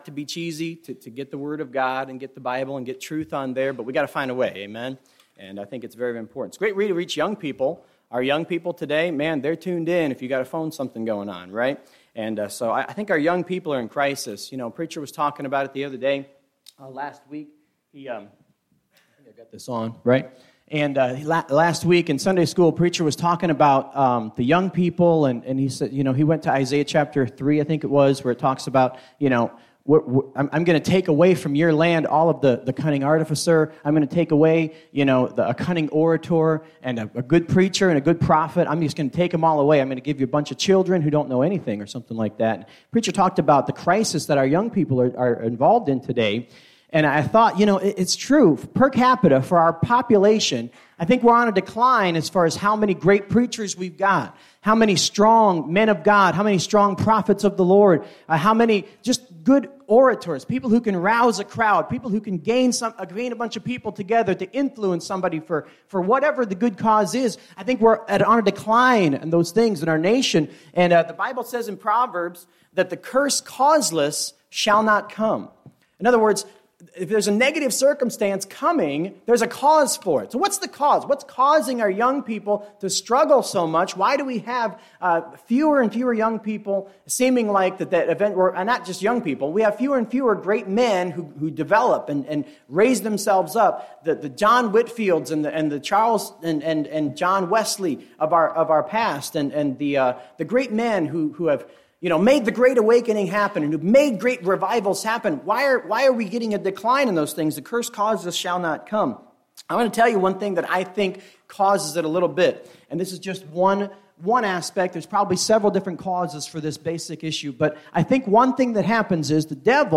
A message from the series "Miscellaneous."